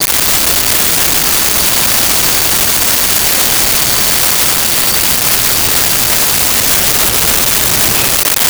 Train Brakes
Train Brakes.wav